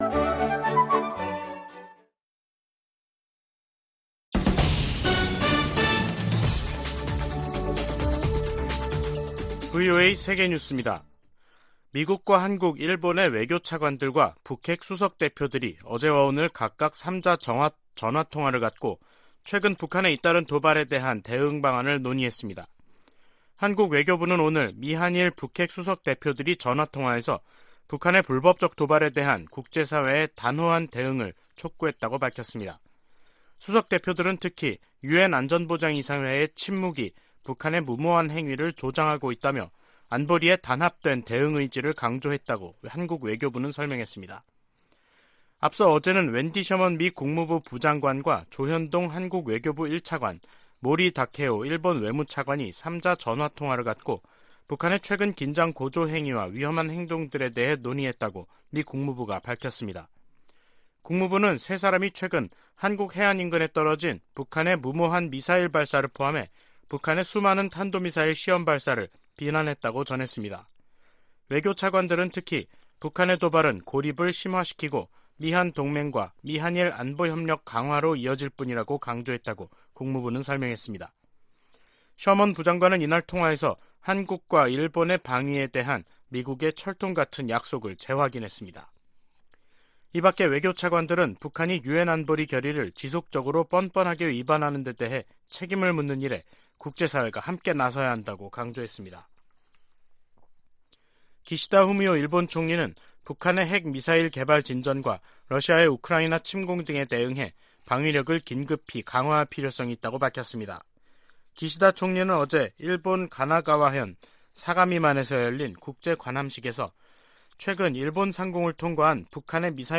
VOA 한국어 간판 뉴스 프로그램 '뉴스 투데이', 2022년 11월 7일 2부 방송입니다. 북한이 미한 연합공중훈련 ‘비질런트 스톰’에 대응한 자신들의 군사작전 내용을 대내외 매체를 통해 비교적 상세히 밝혔습니다. 유엔 안보리가 대륙간탄도미사일(ICBM)을 포함한 북한의 최근 탄도미사일 발사에 대응한 공개회의를 개최하고 북한을 강하게 규탄했습니다. 미국은 북한의 계속되는 도발을 우려하며 심각하게 받아들이고 있다고 백악관 고위관리가 밝혔습니다.